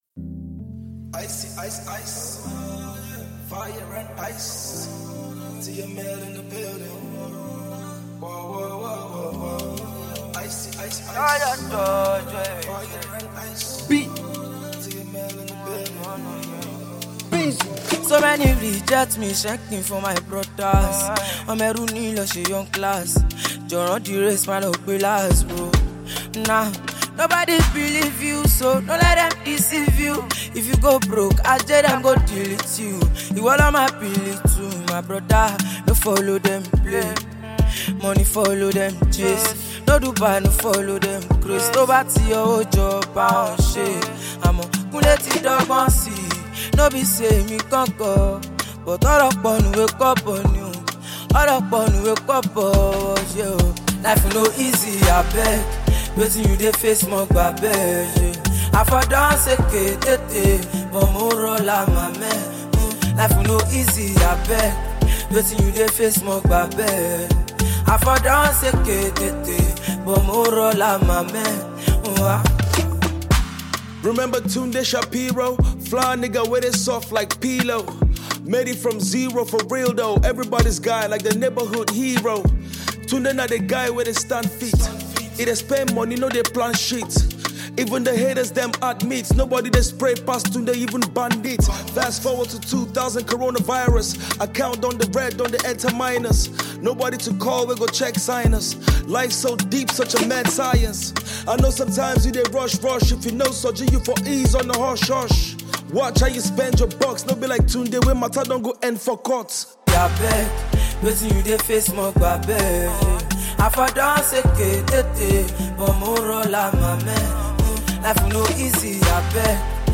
Nigerian / African Music
African Music Genre: Afrobeats Released